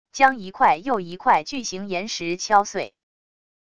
将一块又一块巨型岩石敲碎wav音频